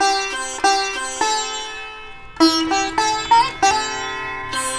100-SITAR1-R.wav